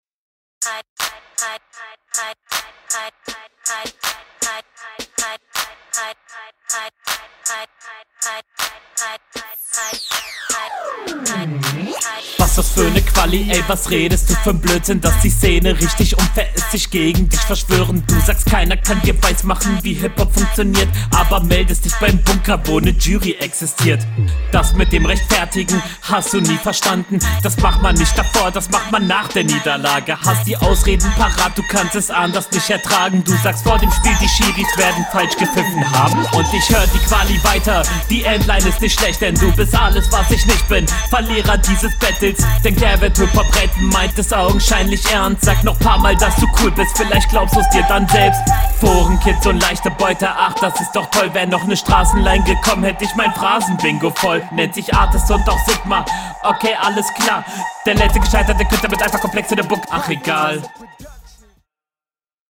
Bisschen Template Type Flow idk.